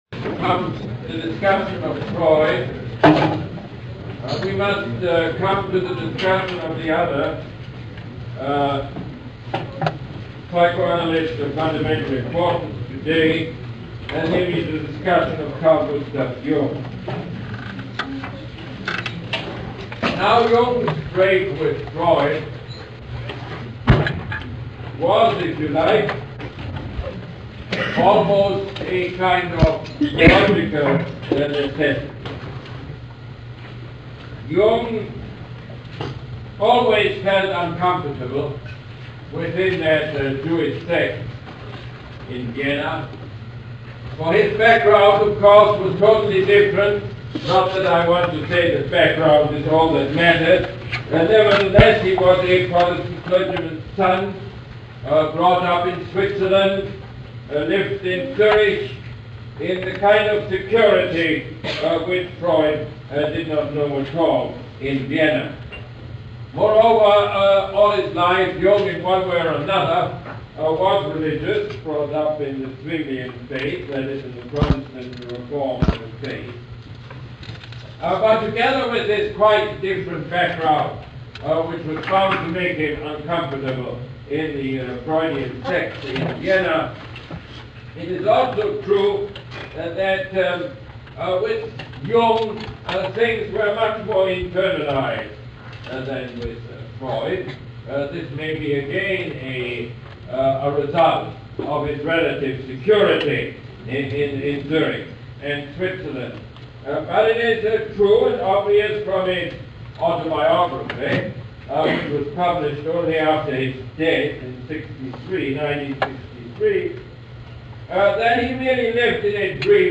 Lecture #12 - October 19, 1979